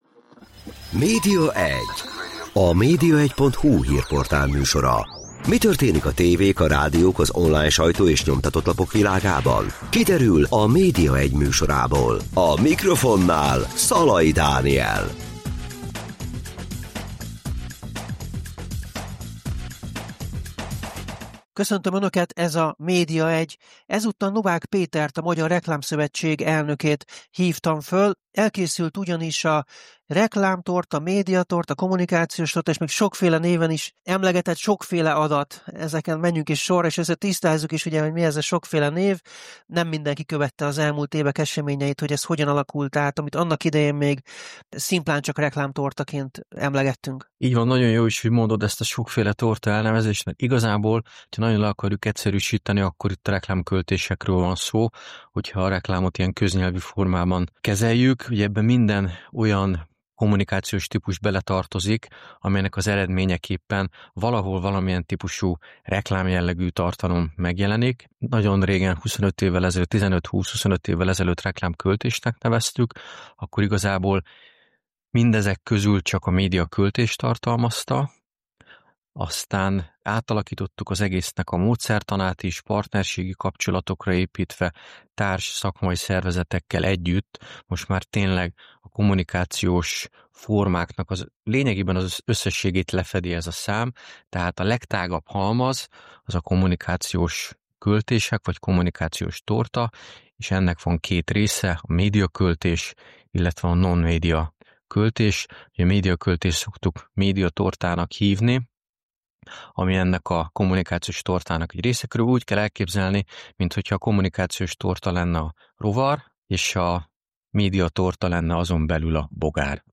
Így áll a magyar reklámipar – Interjú
(A felvétel a Media1 heti podcastműsorát terjesztő rádiókban korábban már hallható volt áprilisban.)